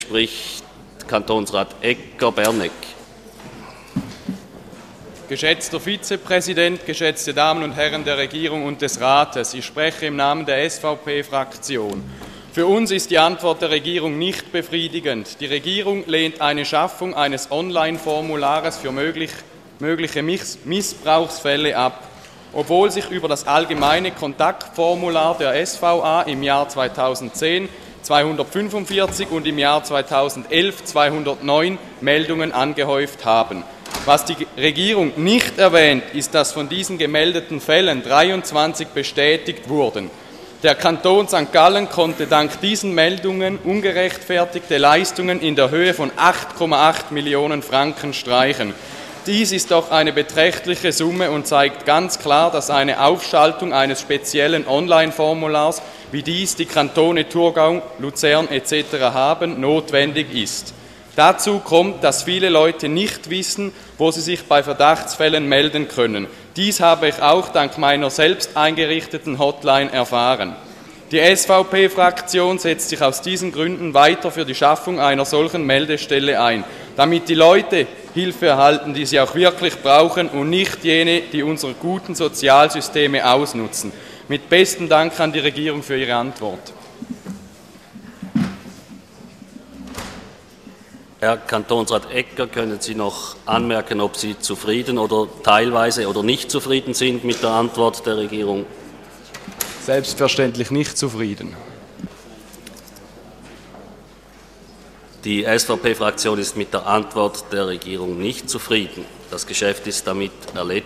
25.9.2012Wortmeldung
Session des Kantonsrates vom 24. und 25. September 2012